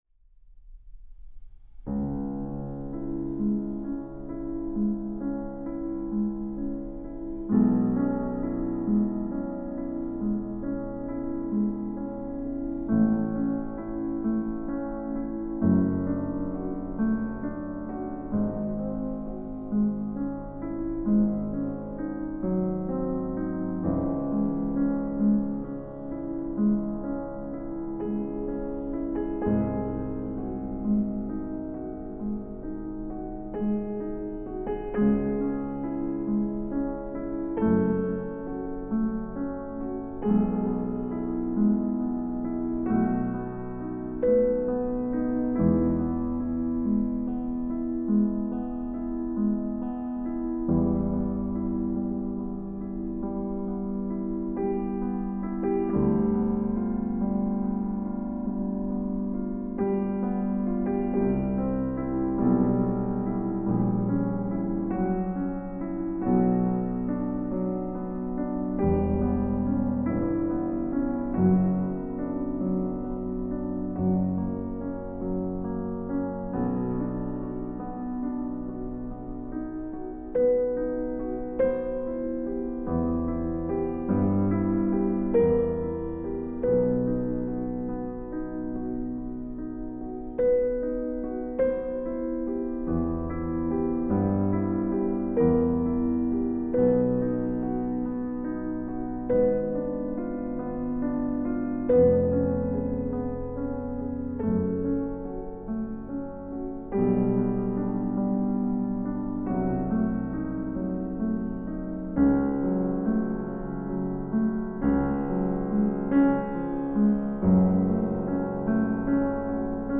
Volume 3. of my recording of the complete Beethoven Piano Sonatas has been released.
But, well, in the end there was variety in the mood, tempo, sound, and phrasing between the different takes.
moonshine_in_one_take_louder.mp3